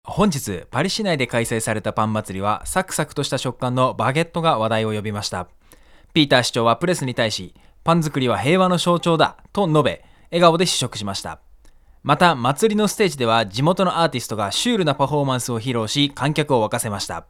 音声収録は全てH6 Essentialに同時録音をしたので読み上げの誤差は全く無い状態だ。
はっきり言ってポンコツ耳の僕だが､少なくともShure MV7･Sennheiser MKE600･FIFINE Amplitank K688これら3つの音声の違いはほぼ感じられなかった。
【マイク3(FIFINE Amplitank K688)】